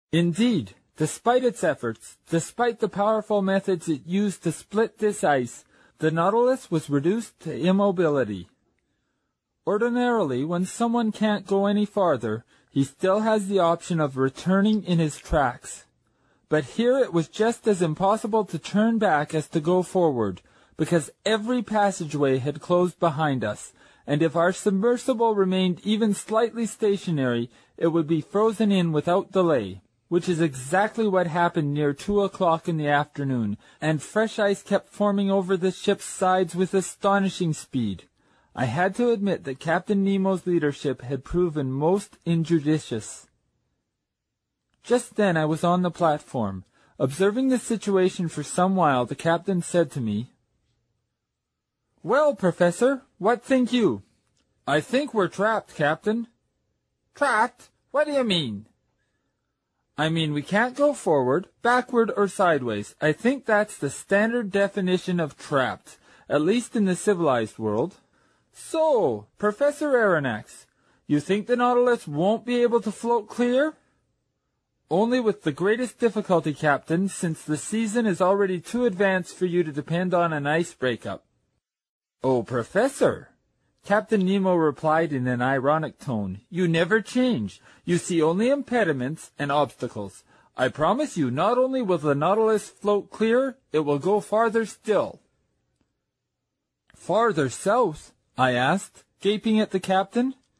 在线英语听力室英语听书《海底两万里》第434期 第26章 大头鲸和长须鲸(15)的听力文件下载,《海底两万里》中英双语有声读物附MP3下载